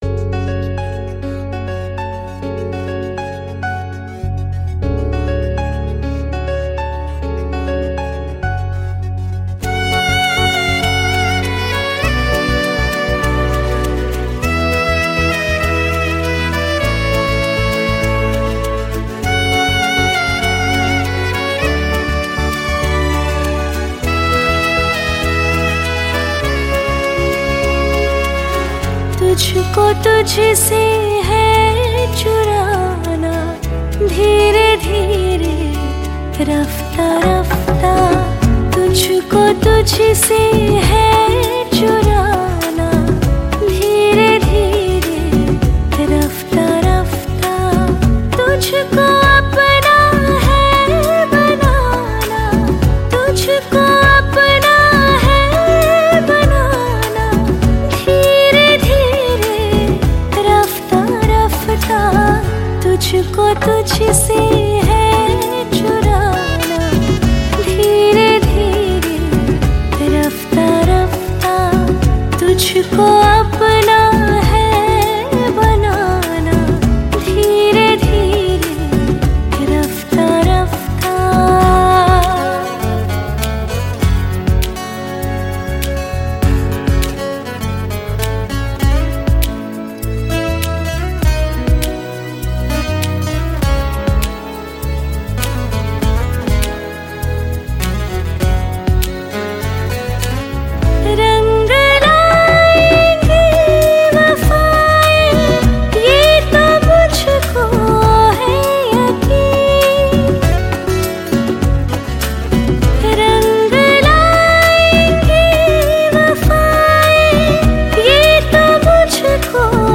Hindi Pop Album Songs